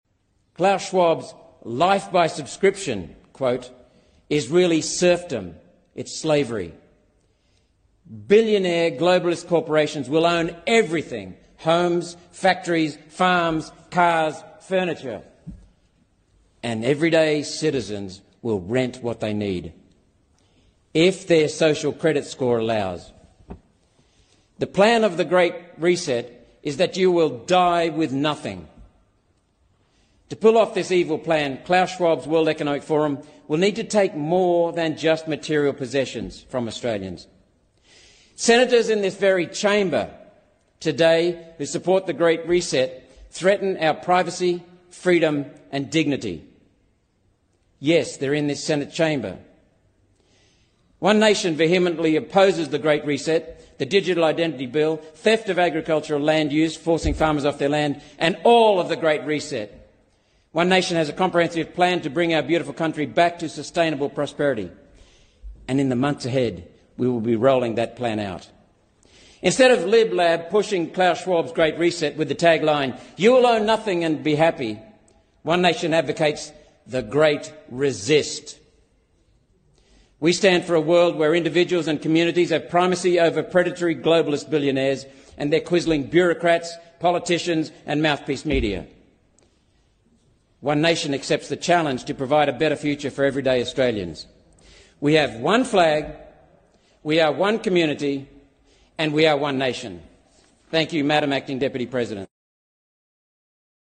הסנאטור האוסטרלי מלקולם רוברטס ממפלגת אומה אחת מאתגר את האיפוס הגדול בפרלמנט האוסטרלי